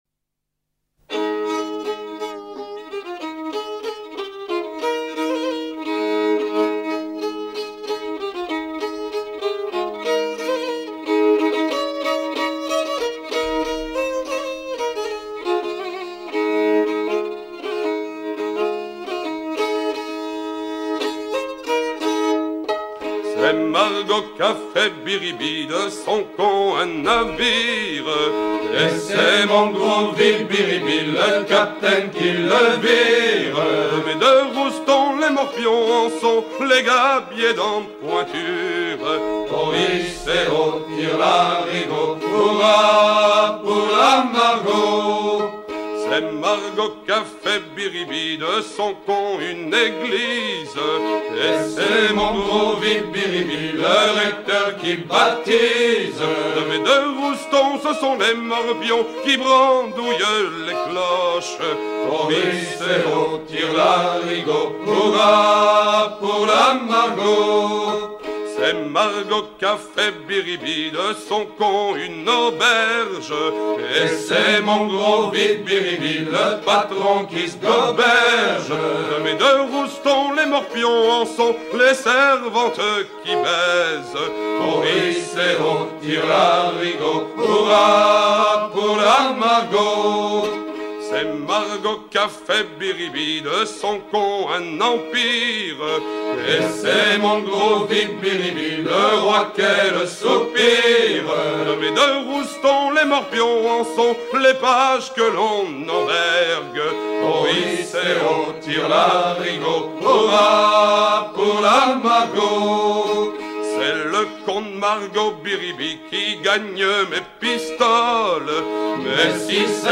Fonction d'après l'analyste gestuel : à virer au cabestan ;
Genre laisse